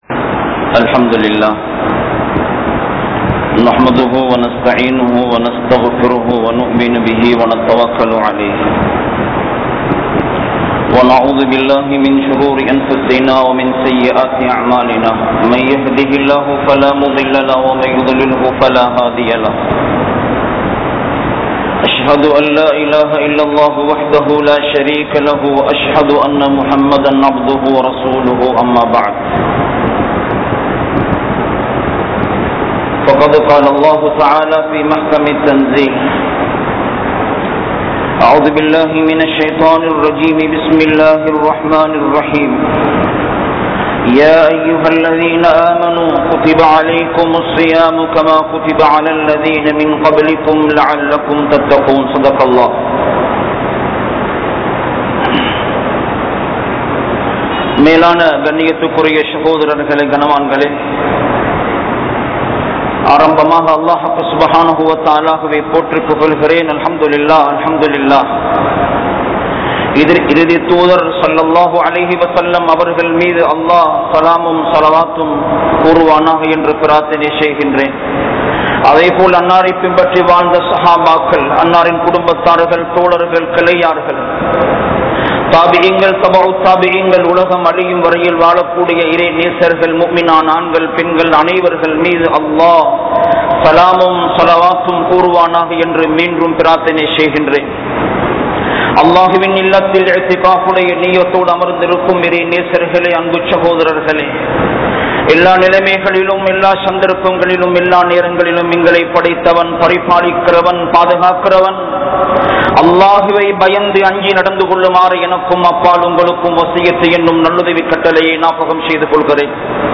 Paavangalai Vittu Vidungal | Audio Bayans | All Ceylon Muslim Youth Community | Addalaichenai